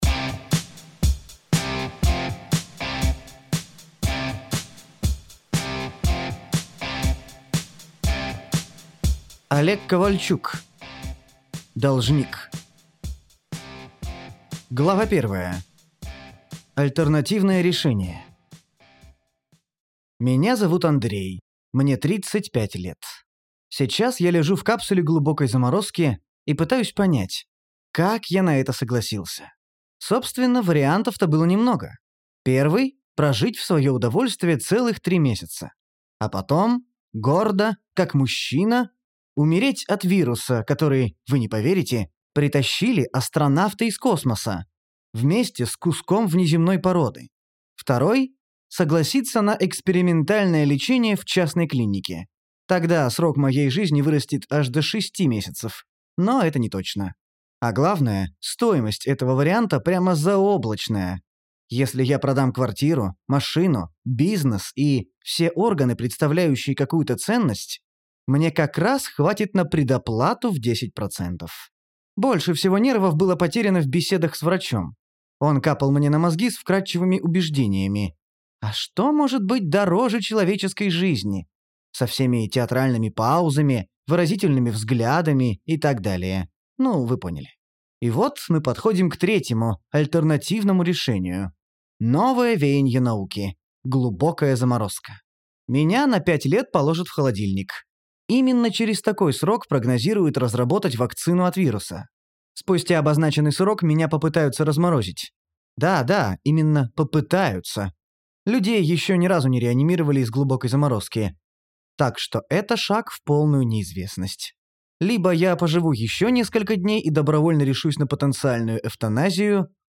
Аудиокнига Должник - Скачать книгу, слушать онлайн